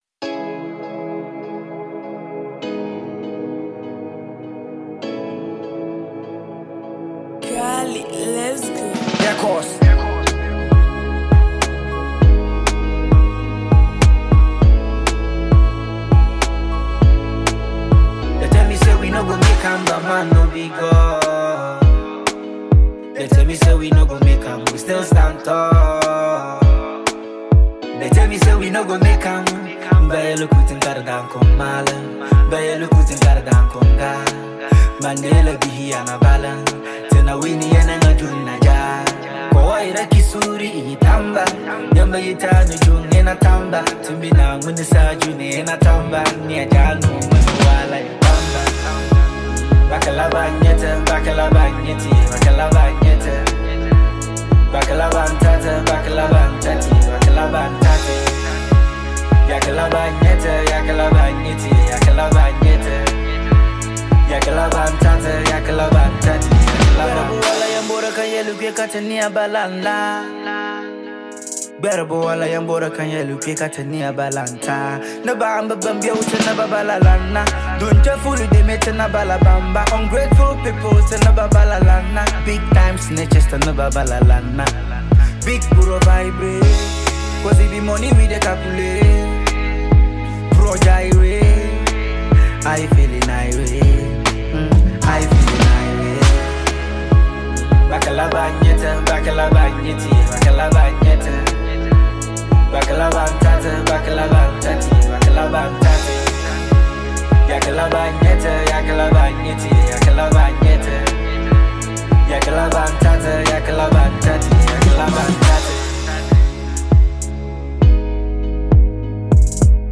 a high-rated Northern-based rapper and songwriter